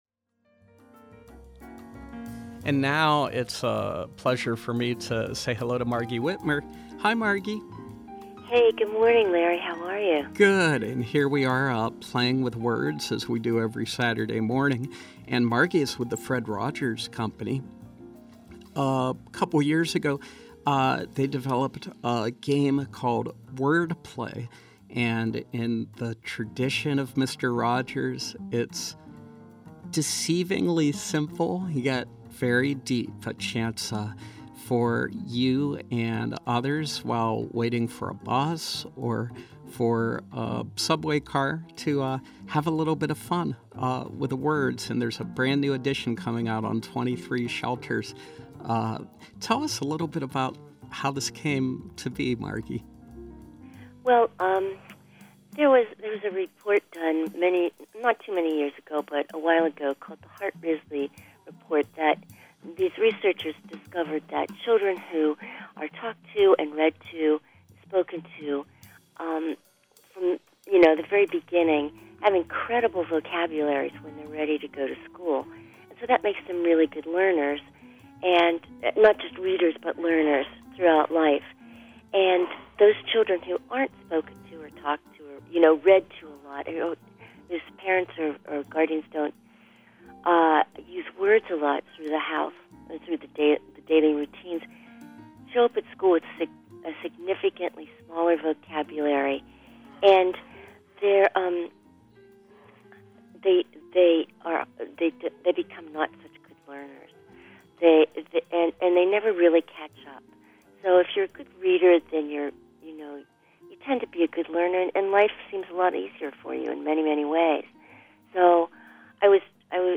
Home » Callers, Interviews